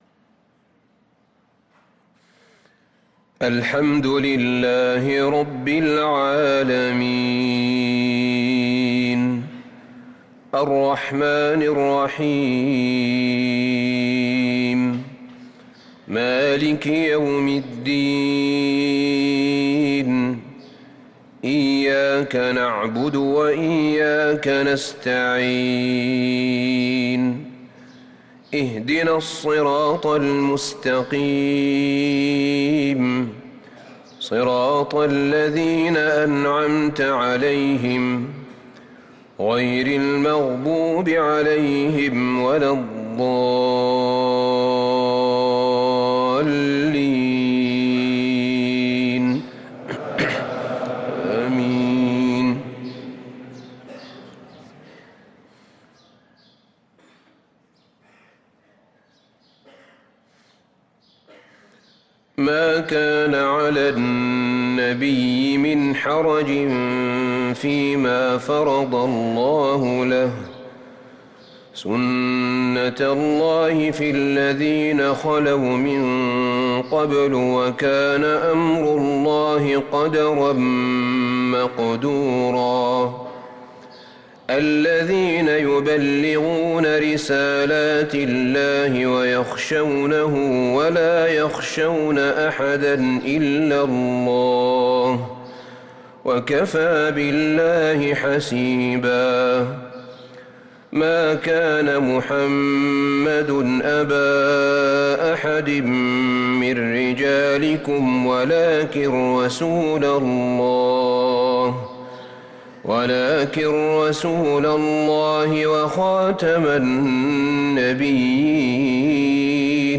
صلاة المغرب للقارئ أحمد بن طالب حميد 10 شعبان 1444 هـ
تِلَاوَات الْحَرَمَيْن .